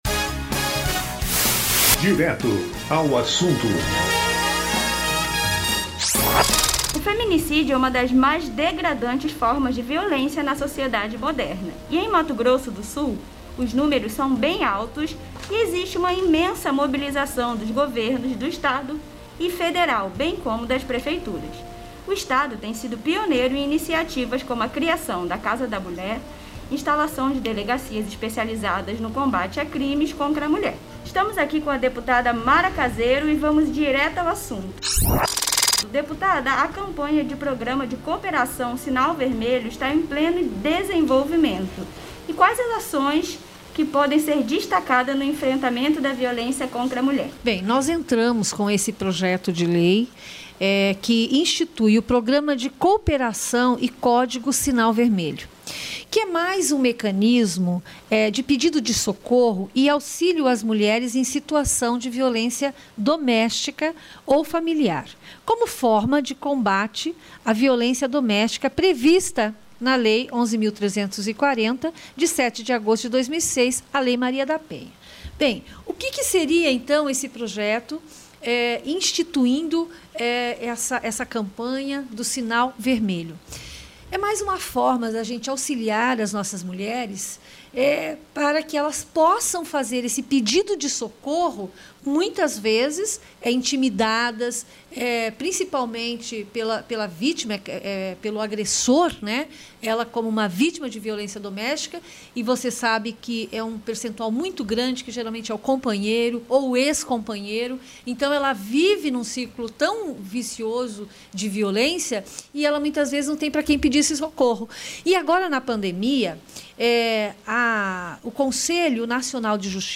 O programa Direto ao Assunto da Rádio ALEMS, que vai ao ar nesta sexta-feira (25), traz uma entrevista com a deputada estadual Mara Caseiro (PSDB), que é autora do projeto que institui no Estado o Programa de Cooperação e Código Sinal Vermelho – como mais uma ferramenta de pedido de socorro e auxílio às mulheres vítimas de violência doméstica e familiar.